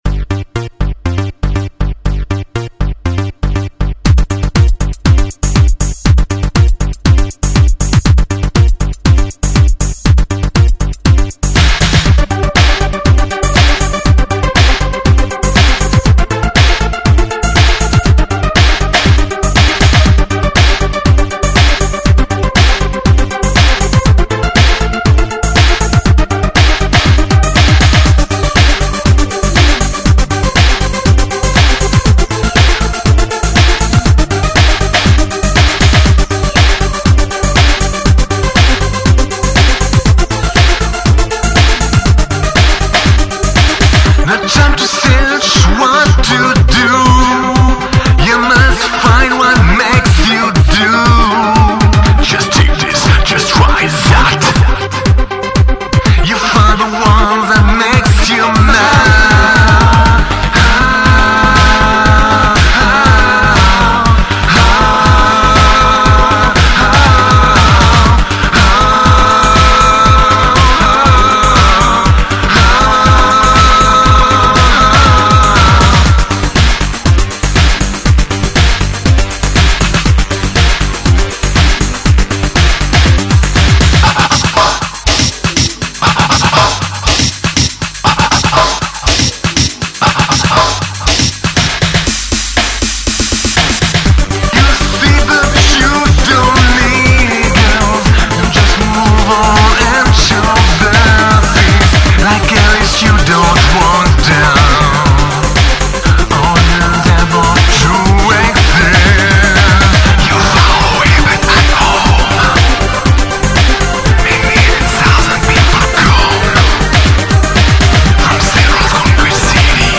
All the following songs/samples have been degraded.